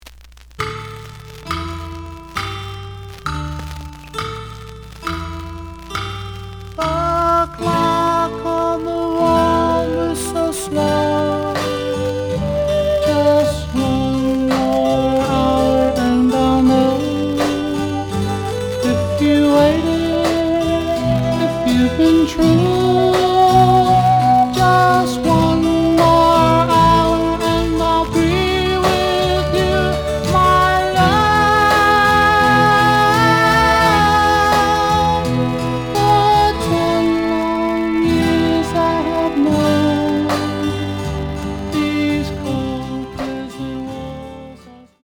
The audio sample is recorded from the actual item.
●Genre: Rock / Pop
Slight noise on B side.